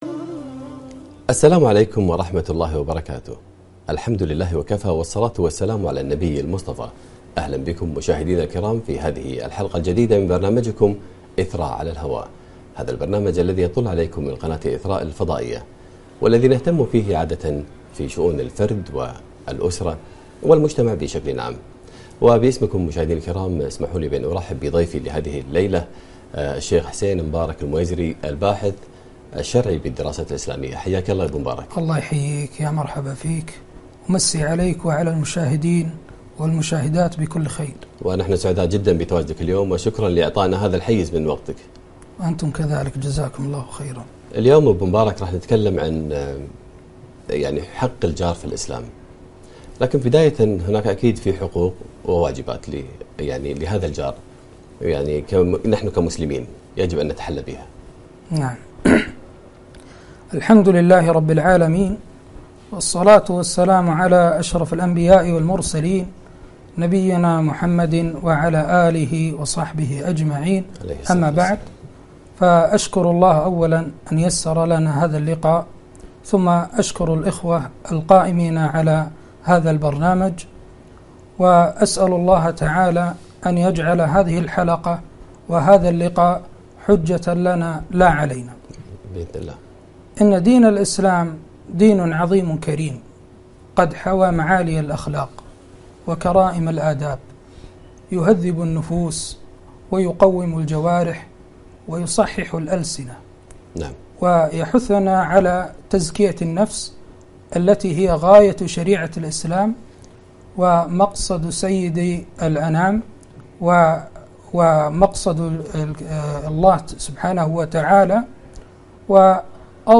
حق الجار في الإسلام لقاء عبر قناة إثراء الفضائية